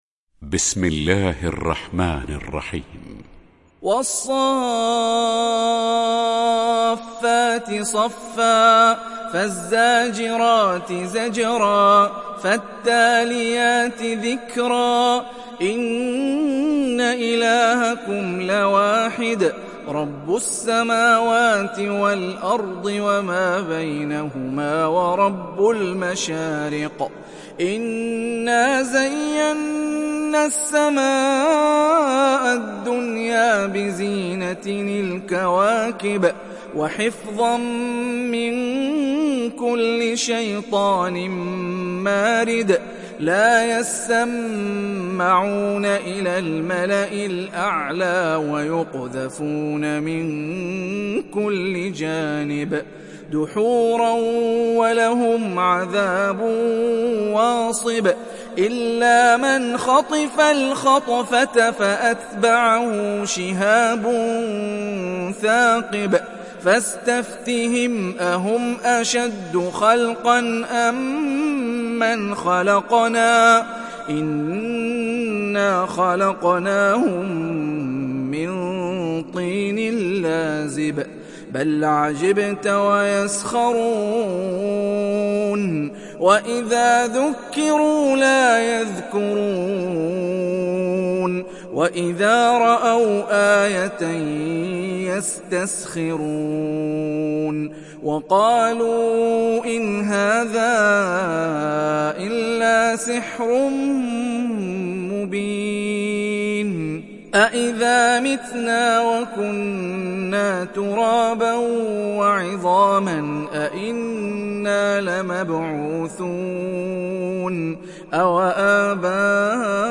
تحميل سورة الصافات mp3 بصوت هاني الرفاعي برواية حفص عن عاصم, تحميل استماع القرآن الكريم على الجوال mp3 كاملا بروابط مباشرة وسريعة